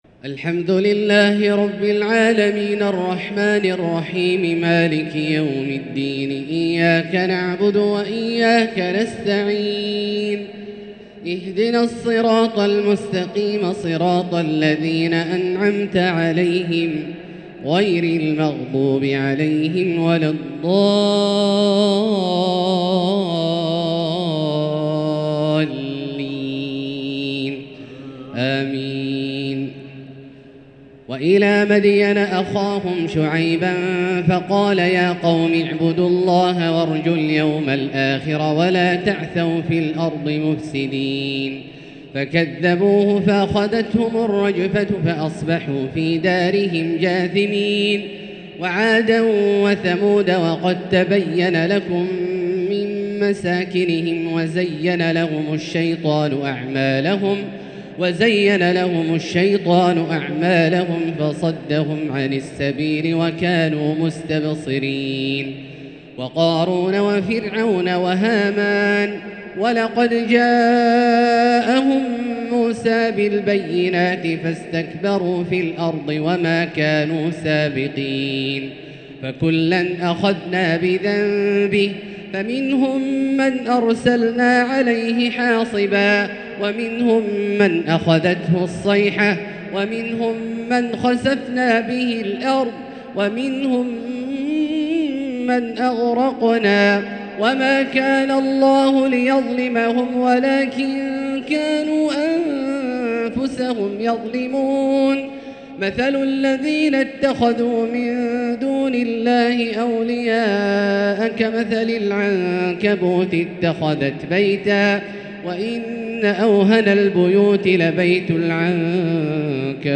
تراويح ليلة 24 رمضان 1444هـ من سورتي العنكبوت (36-69) و الروم (1-45) | taraweeh 24 st night Ramadan 1444H Surah Al-Ankaboot and Ar-Room > تراويح الحرم المكي عام 1444 🕋 > التراويح - تلاوات الحرمين